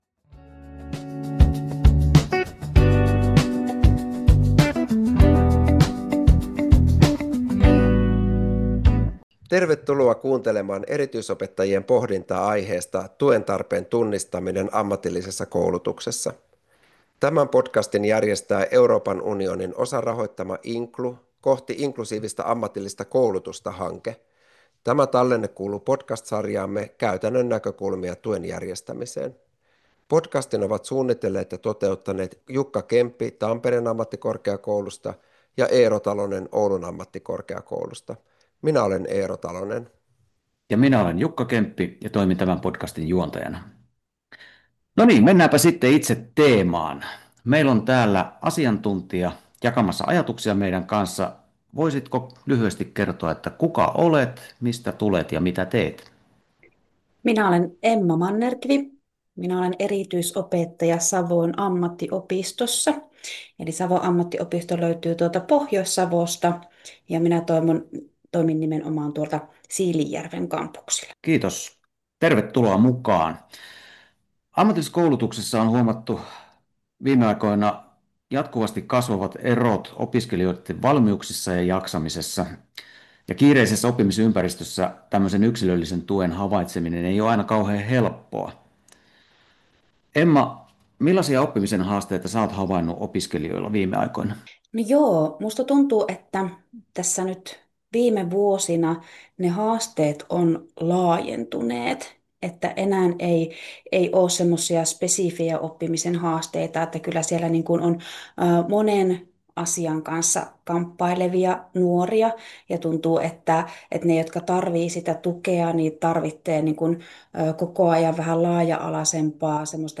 Aihetta käsittelevät kolme erityisopetuksen asiantuntijaa